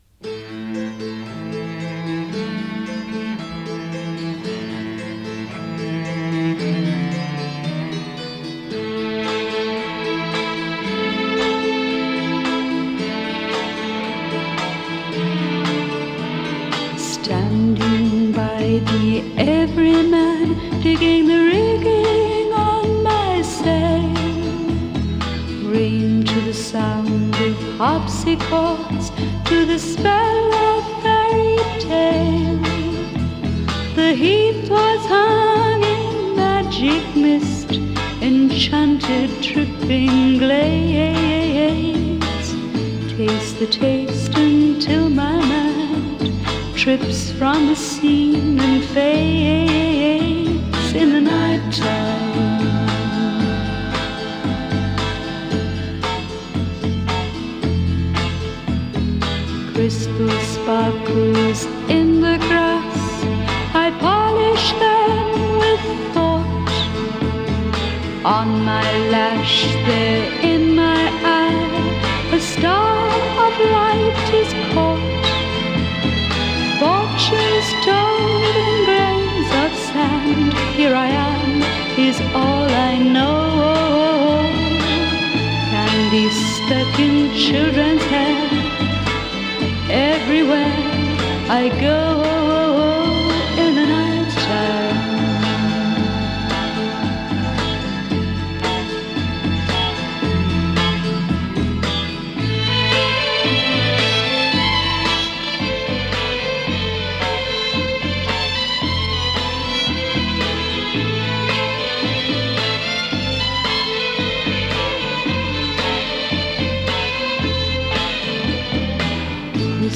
ソフトロック